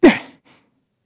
Kibera-Vox_Jump_kr.wav